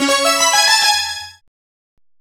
Synth Lick 49-03.wav